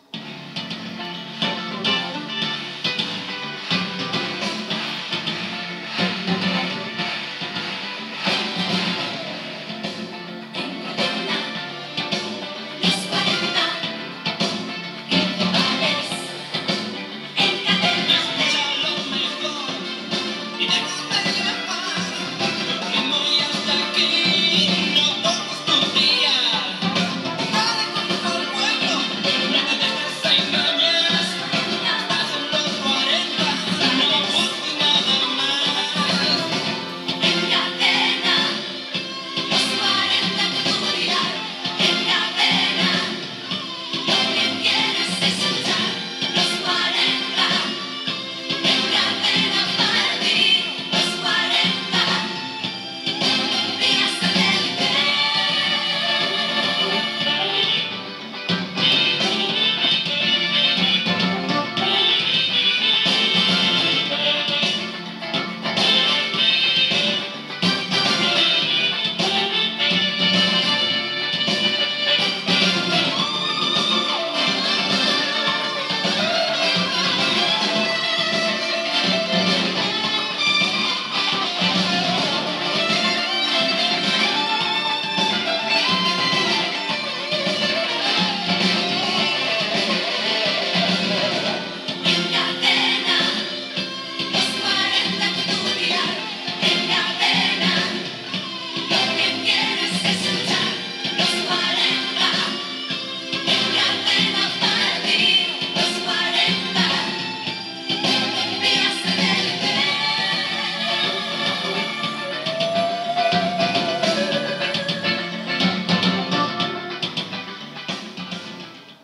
Indicatiu dels 25 anys del programa